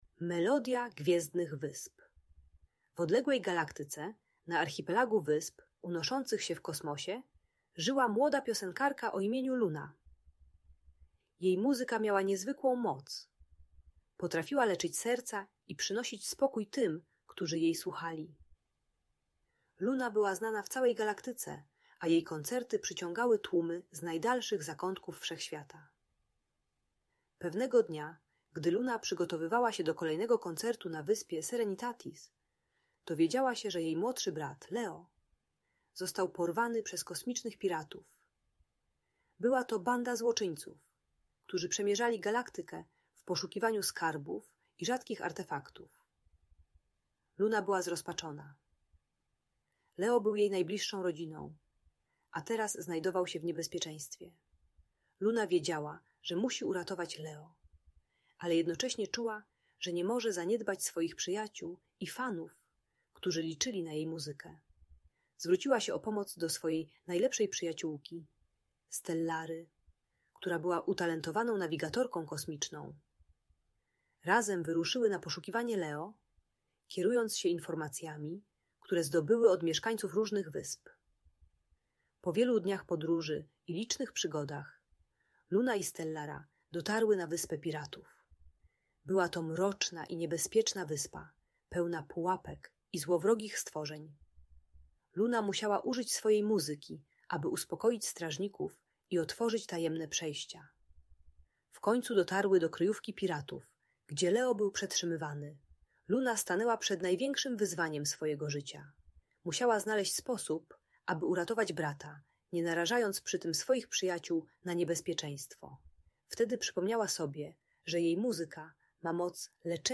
Melodia Gwiezdnych Wysp - Rodzeństwo | Audiobajka